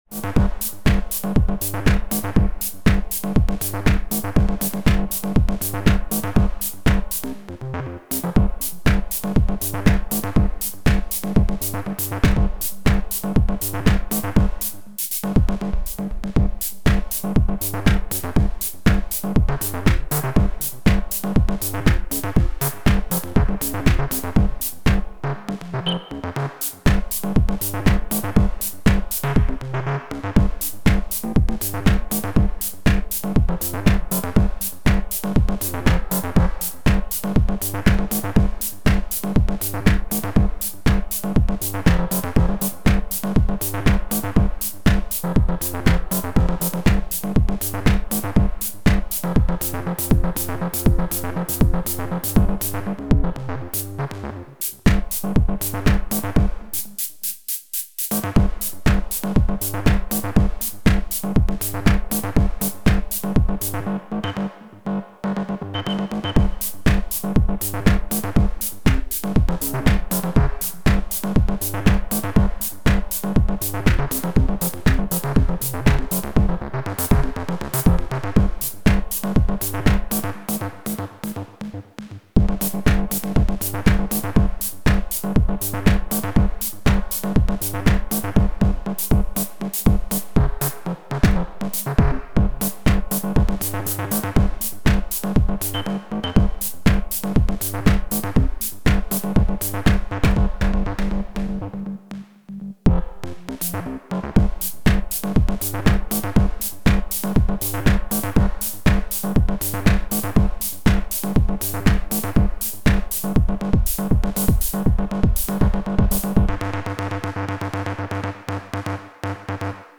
Anyways, here’s a 16 step loop with Pulsar-23 and Cascadia playing a simple pattern, trying out the punch-in FX.